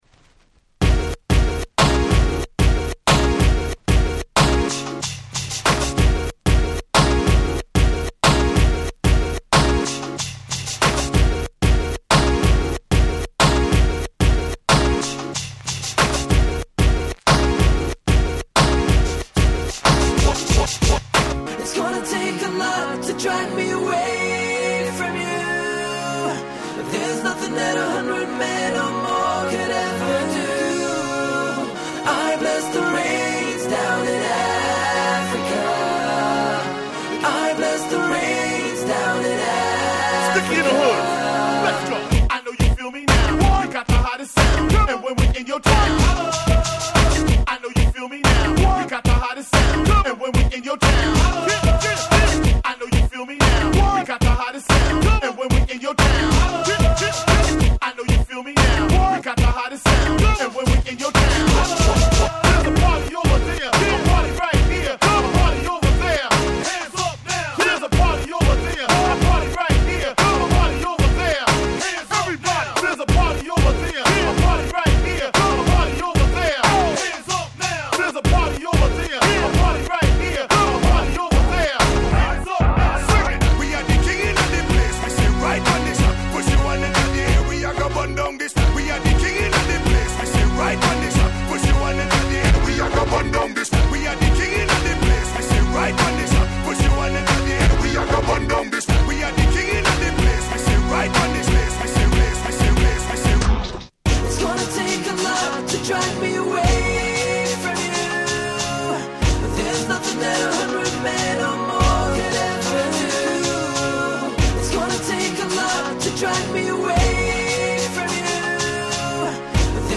大ヒットDancehall R&B !!
00's レゲエ ダンスホール キャッチー系